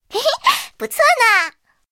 SU-26获得资源语音.OGG